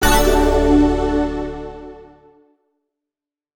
Positive Holy2.wav